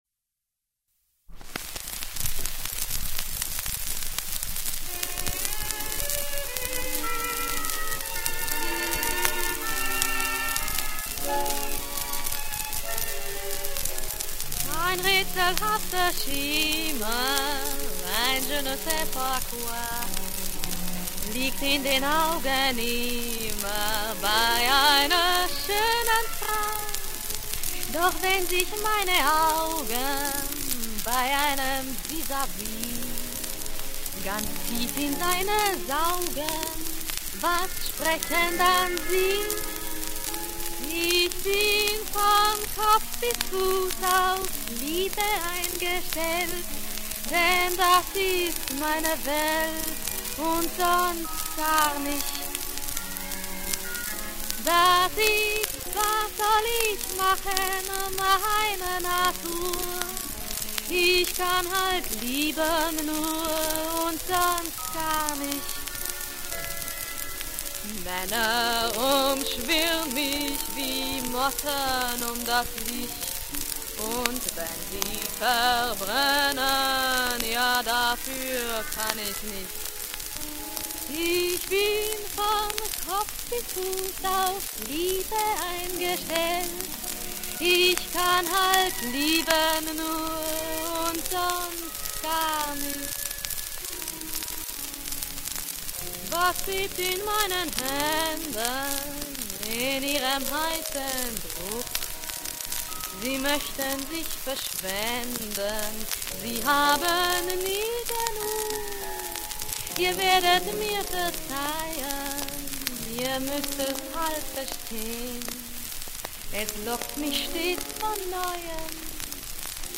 Singing with Orchestra
Recorded in Berlin.